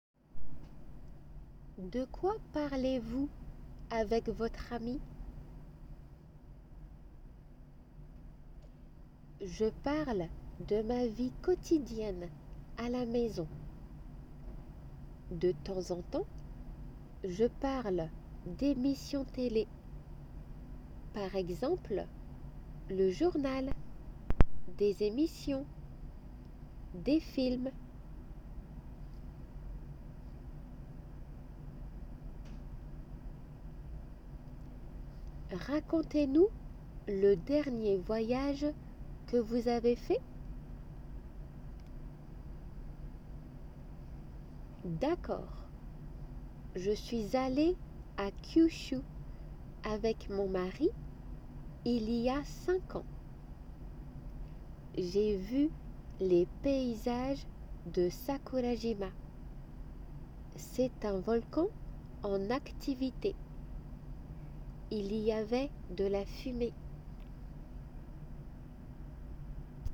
2次試験の受験の際の　耳慣らしと口頭練習の為に。過去問を参考に作成しております。